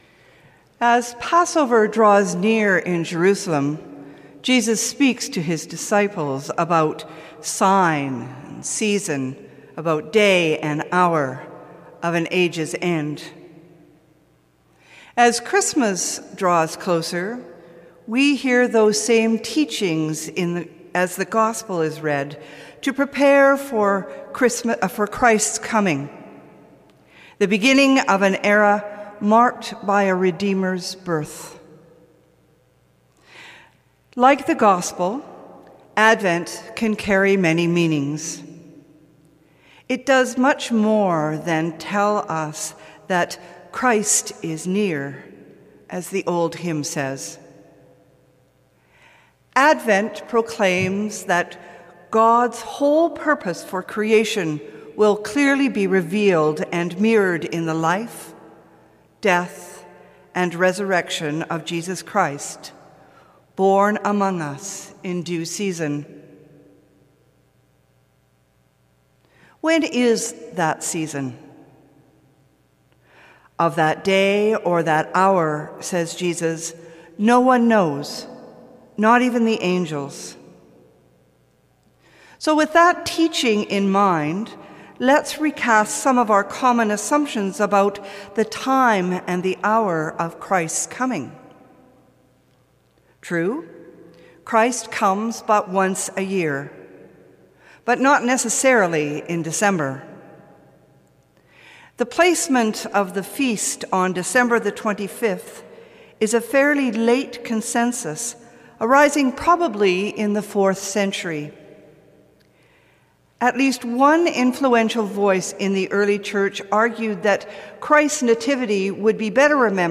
Sermon: 9.15 a.m. service